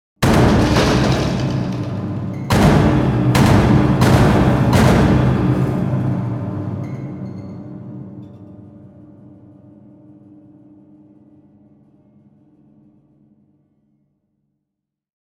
Destruction Impact Sound Effect
Powerful and terrifying destructive hits sound effect. Perfect for horror, action, and intense cinematic scenes.
Destruction-impact-sound-effect.mp3